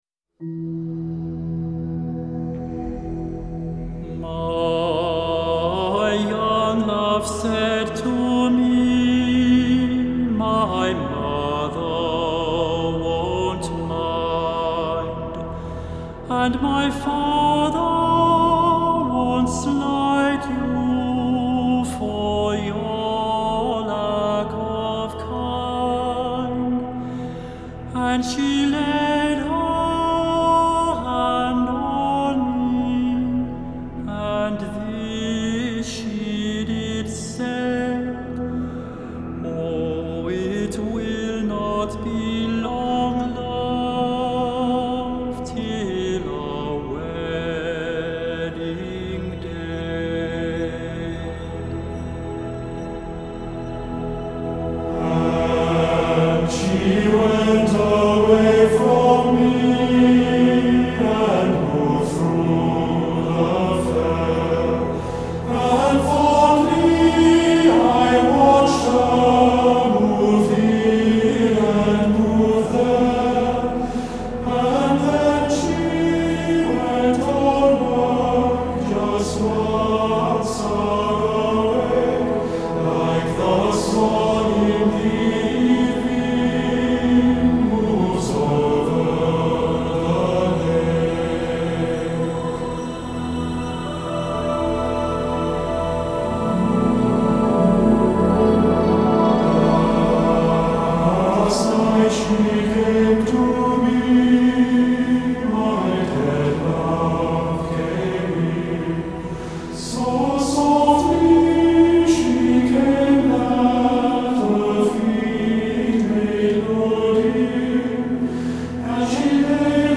Hi-Res Stereo
Genre : Pop